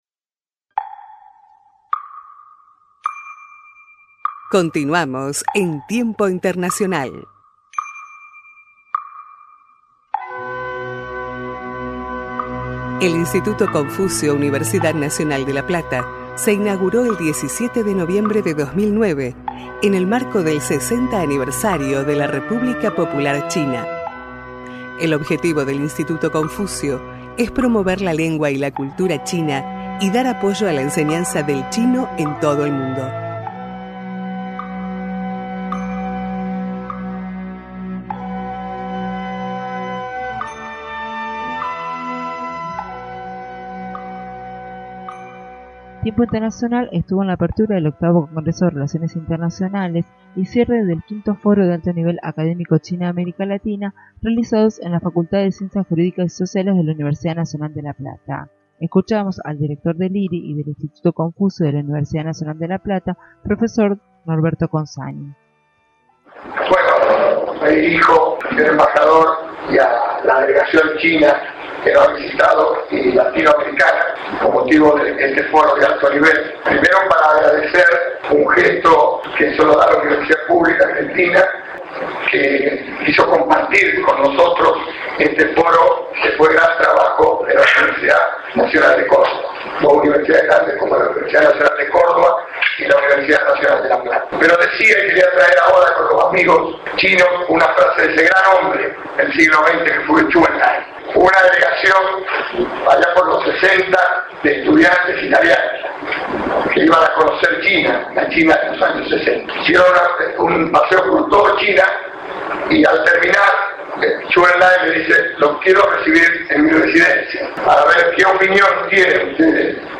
quienes hablaron en el V Foro de Alto Nivel Académico América Latina y China.